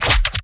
Rocket Launcher
GUNMech_Rocket Launcher Reload_08_SFRMS_SCIWPNS.wav